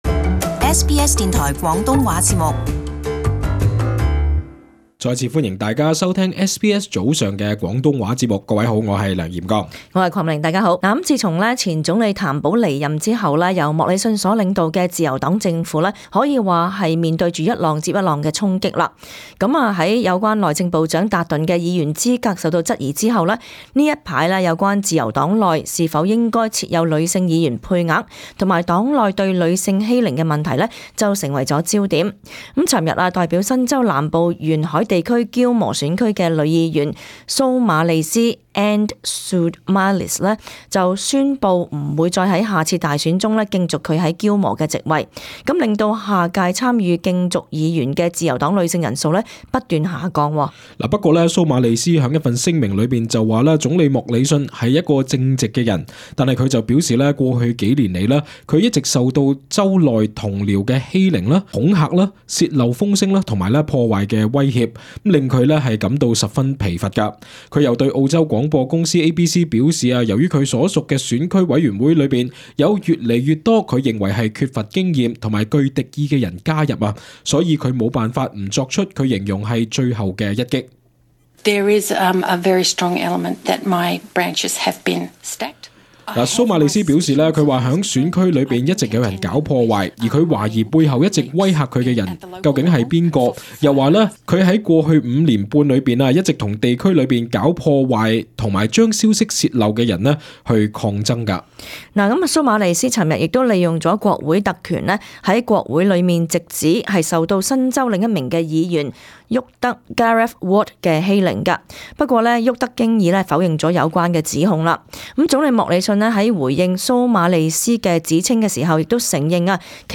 【時事報導】再有自由黨女議員指曾於黨內受欺凌